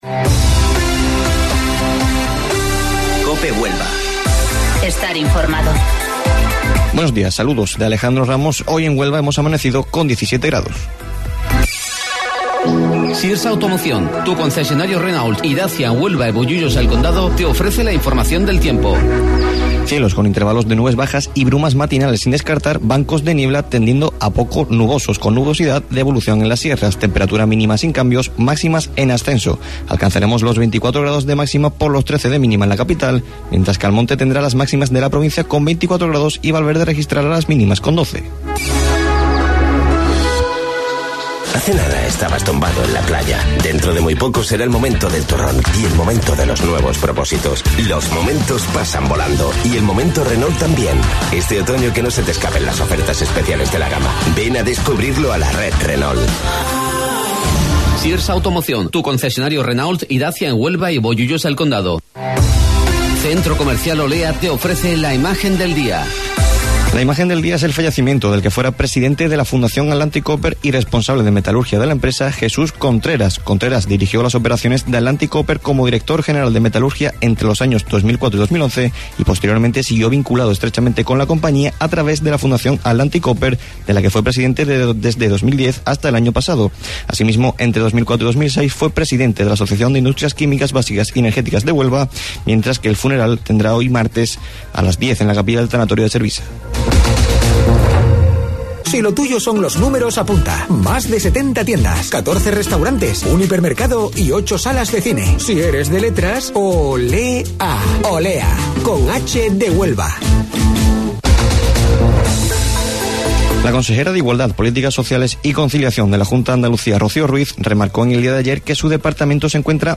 AUDIO: Informativo Local 08:25 del 29 de Octubre